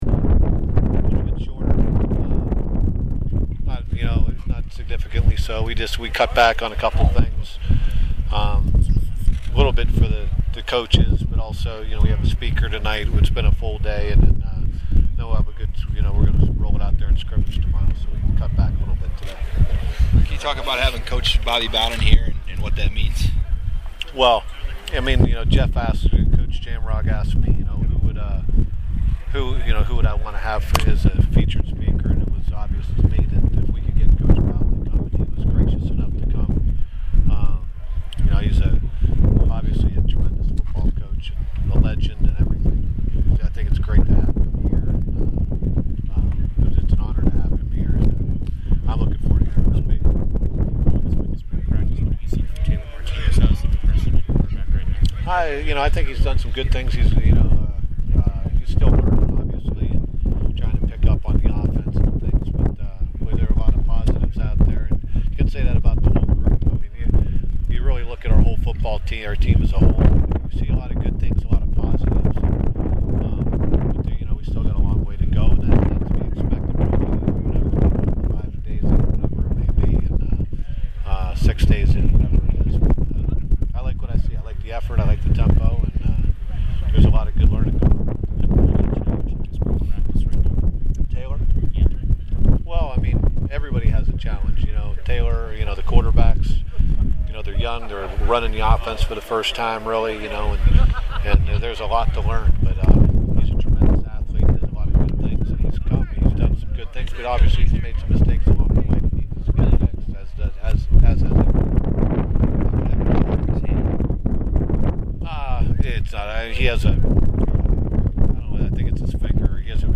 And if you want to know every little bit about that day's practice, you can always listen to the coaches' perspective in the full post-practice audio file.